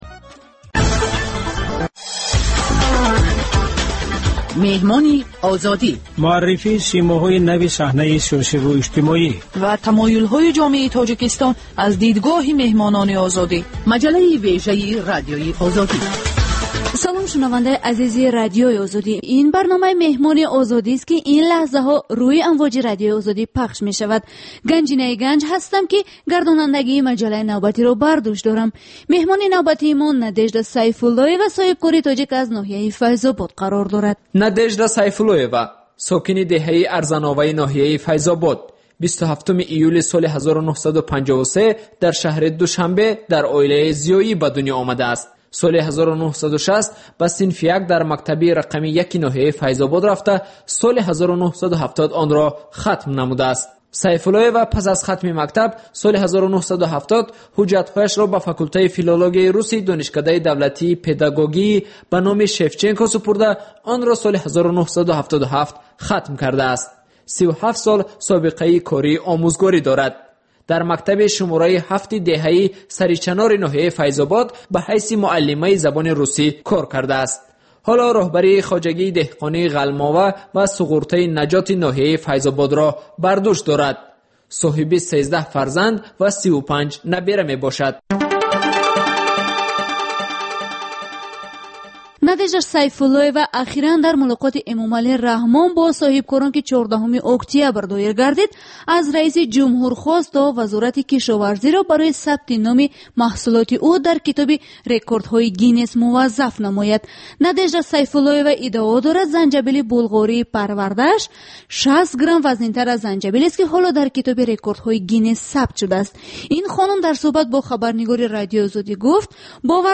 Сӯҳбати ошкоро бо чеҳраҳои саршинос ва мӯътабари Тоҷикистон бо пурсишҳои сангин ва бидуни марз.